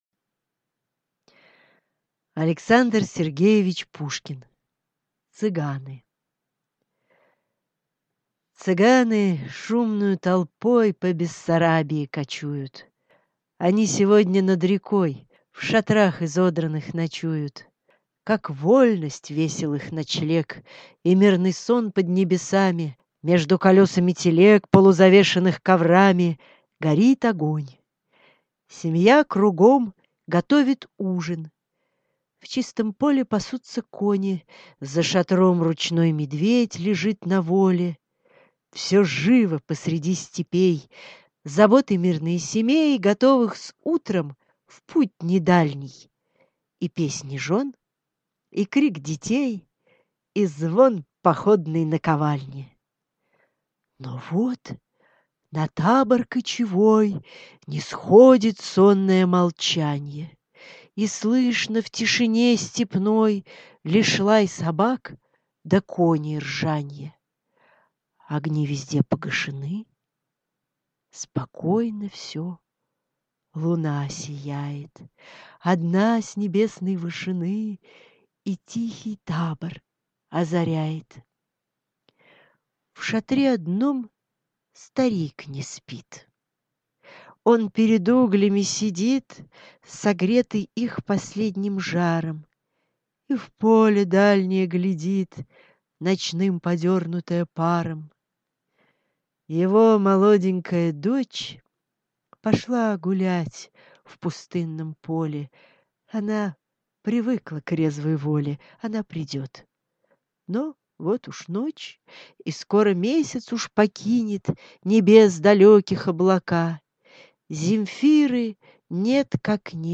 Аудиокнига Цыганы - купить, скачать и слушать онлайн | КнигоПоиск